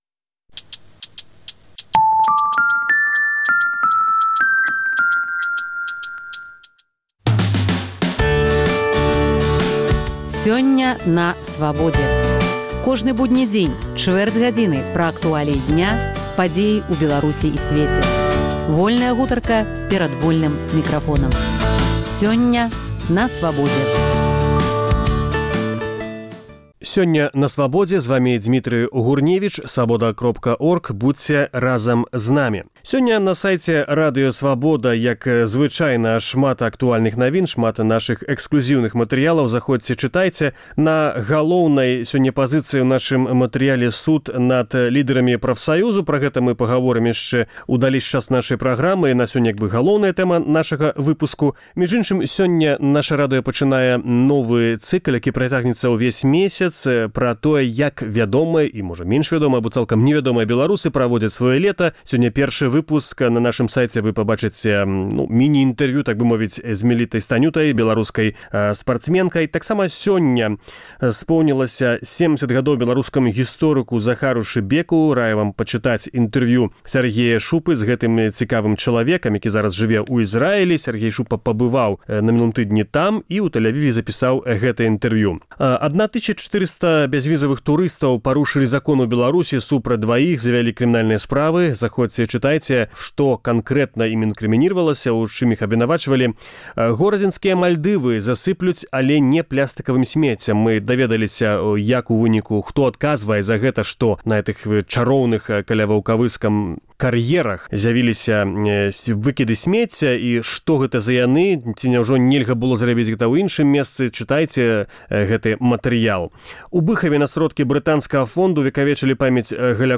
Гутарка на тэмы дня